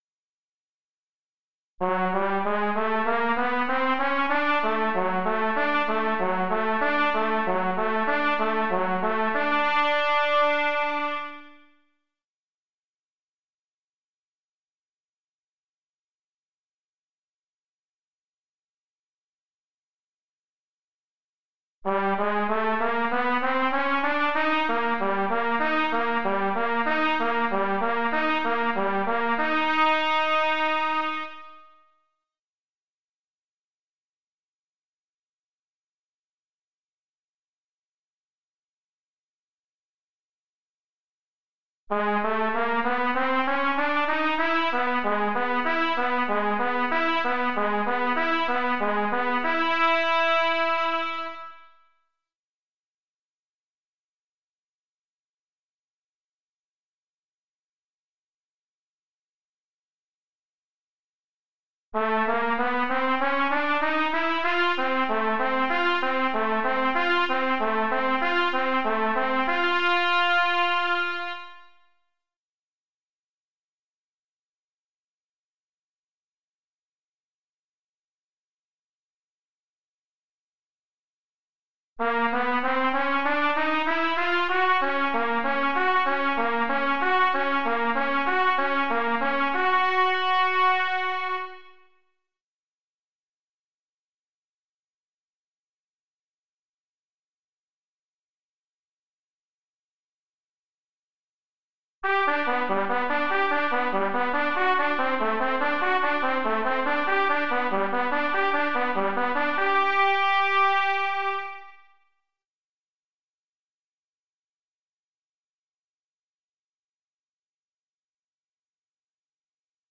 Repeat the “20 Minutes Warmup” but this time continue up to a high “E”. Then continue back down when the recording descends.